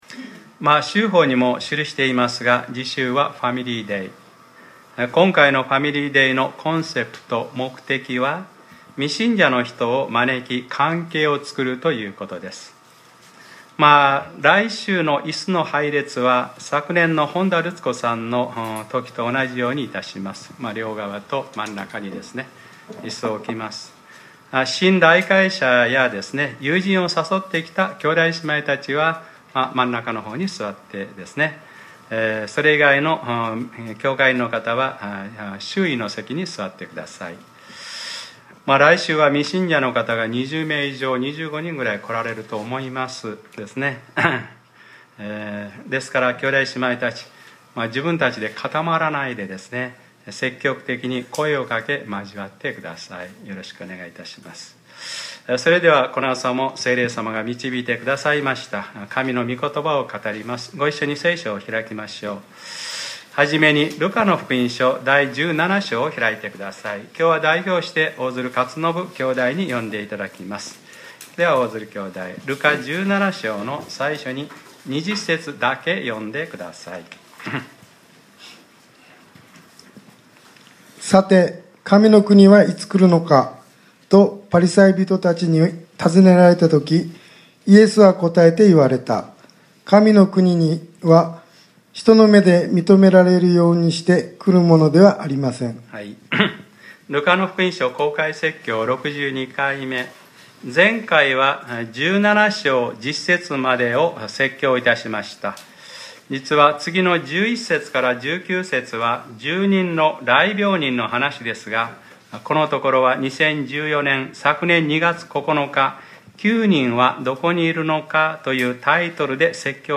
2015年06月14日（日）礼拝説教 『ルカｰ６２：ロトの妻を思い出しなさい』 | クライストチャーチ久留米教会